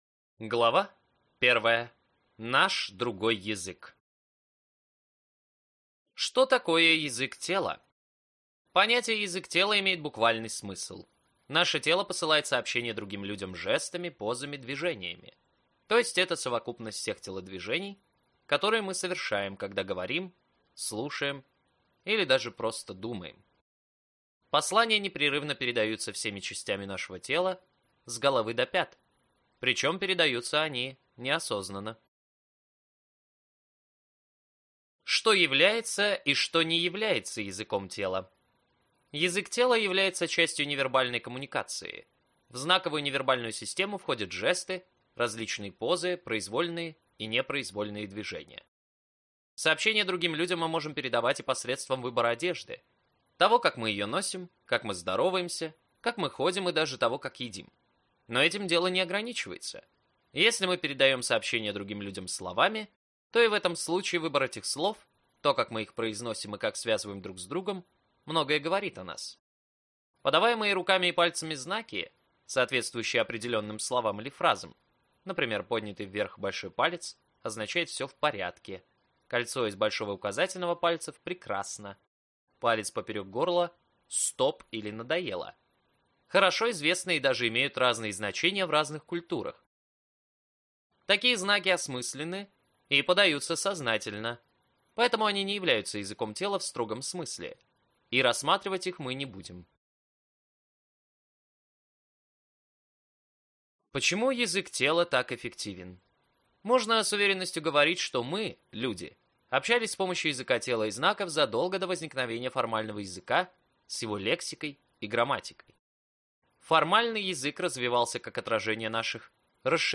Аудиокнига Язык тела. Впечатляйте, убеждайте и добивайтесь успеха с помощью языка тела | Библиотека аудиокниг